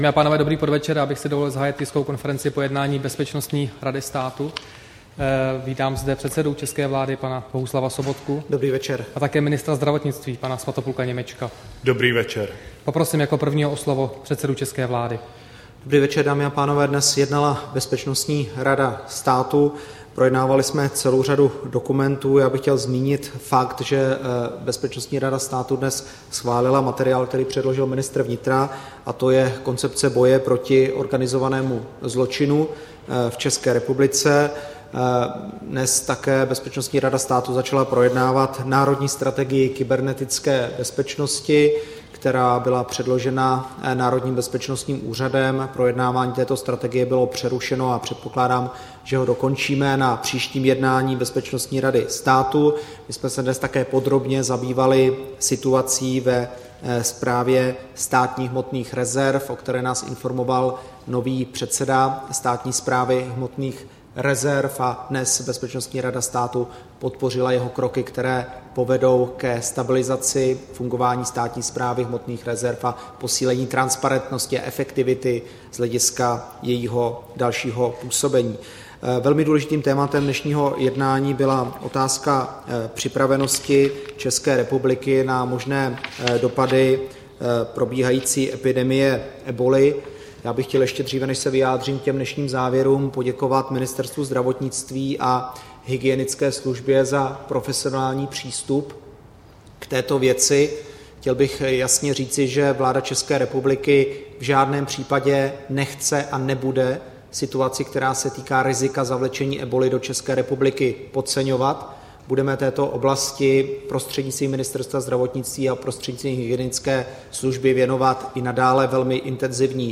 Tisková konference po jednání Bezpečností rady státu, 15. října 2014